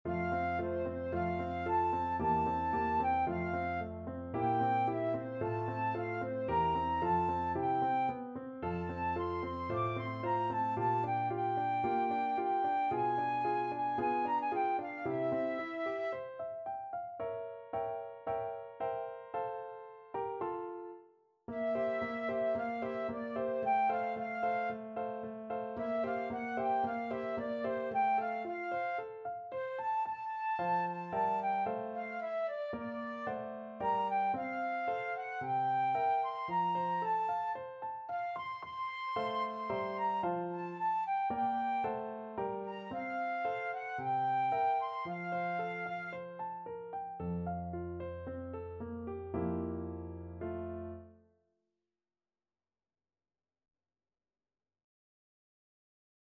2/4 (View more 2/4 Music)
~ = 56 Affettuoso
Classical (View more Classical Flute Music)